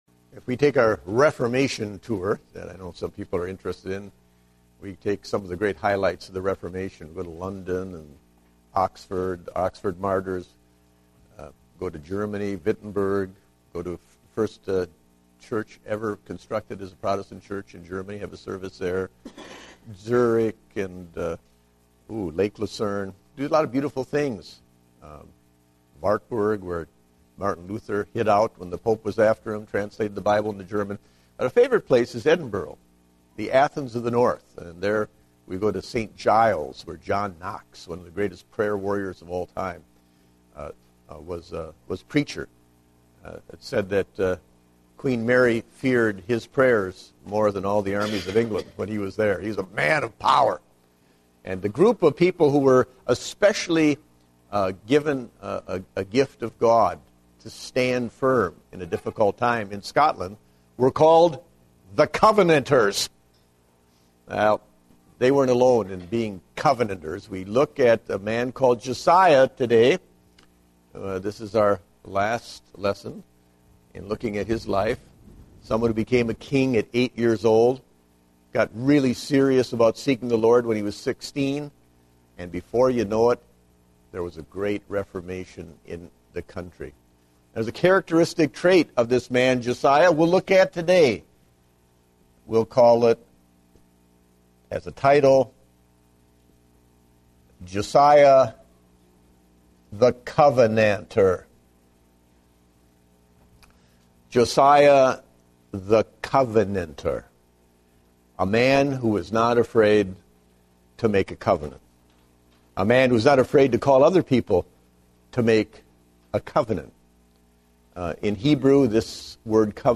Date: March 28, 2010 (Adult Sunday School)